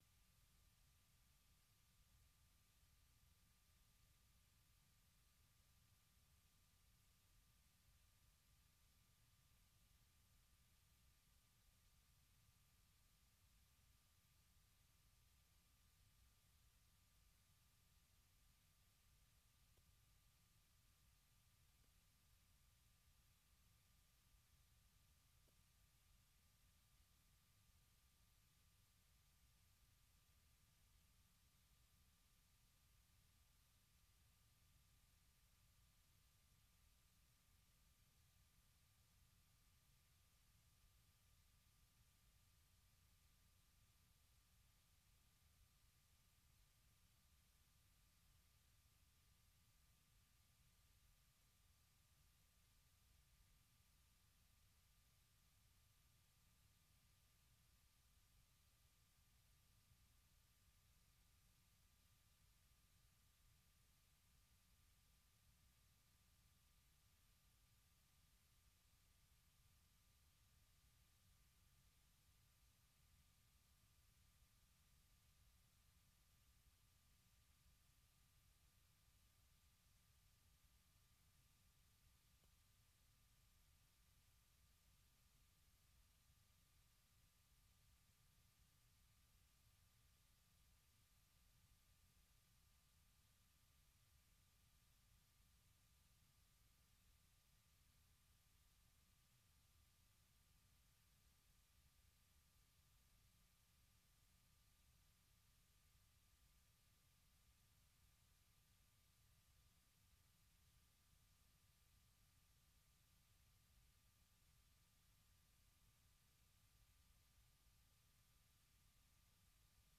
Zouk, Reggae, Latino, Soca, Compas et Afro
interviews de divers artistes